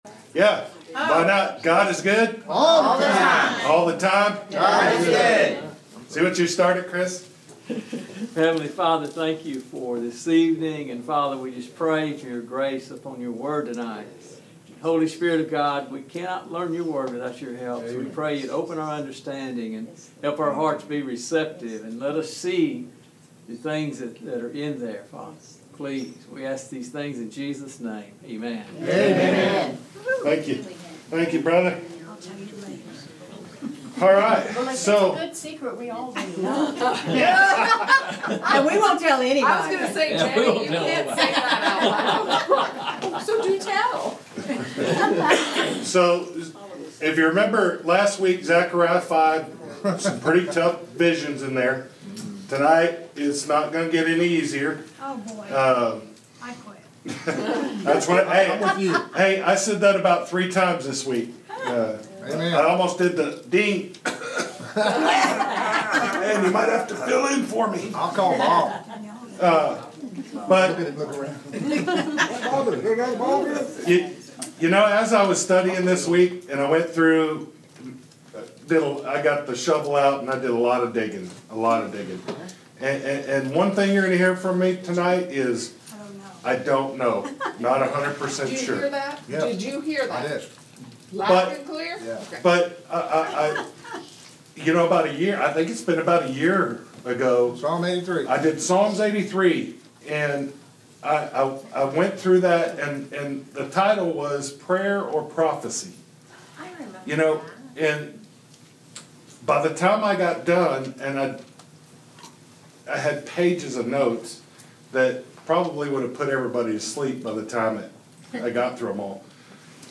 Interactive Bible Study
Sermon